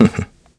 Riheet-Vox-Laugh-02.wav